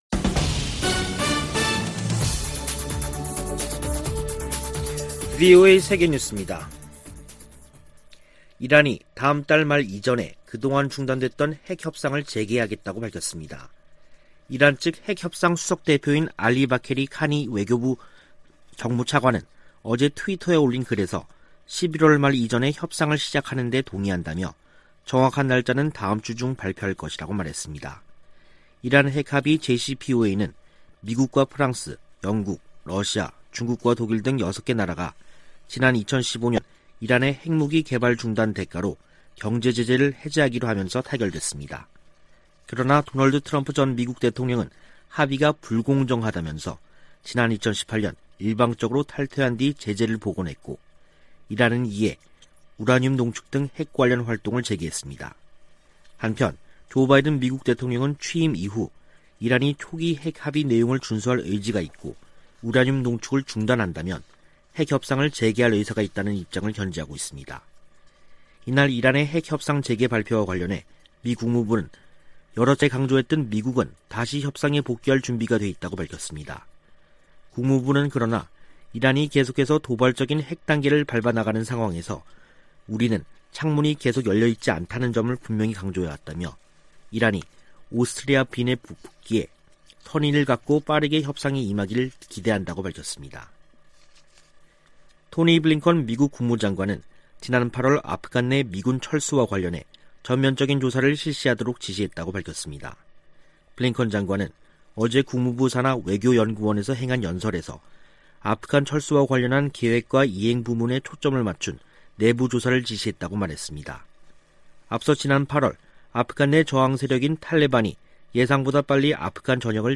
VOA 한국어 간판 뉴스 프로그램 '뉴스 투데이', 2021년 10월 28일 3부 방송입니다. 북한이 종전선언 논의를 위한 선결조건으로 미-한 연합훈련 중단을 요구하고 있다고 한국 국가정보원이 밝혔습니다. 마크 밀리 미 합참의장은 북한이 미사일 등으로 도발하고 있다며, 면밀히 주시하고 있다고 밝혔습니다. 토니 블링컨 미 국무장관이 보건과 사이버 안보 등에 전문성을 갖추고 다자외교를 강화하는 미국 외교 미래 구상을 밝혔습니다.